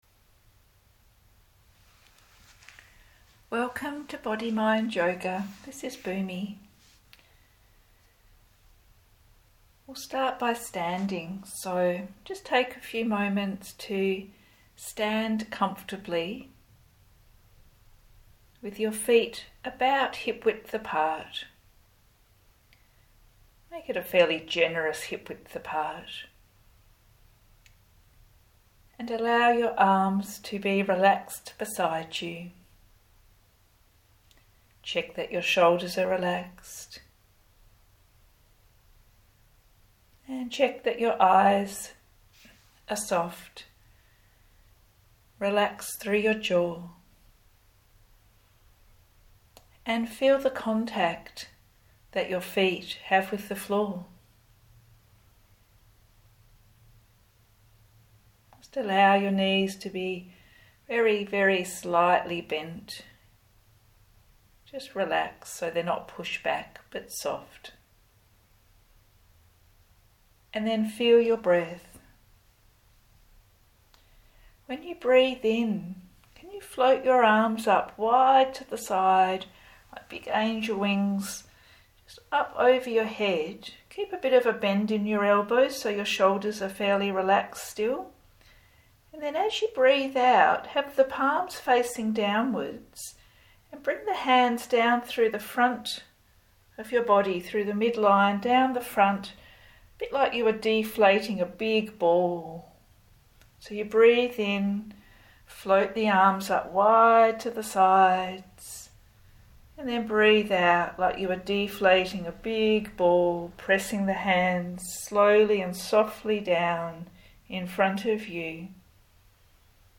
All audio yoga classes and meditations are available on the website as a free download and are a great way to catch up on missed classes or do extra classes each week if you want to.